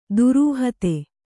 ♪ durūhate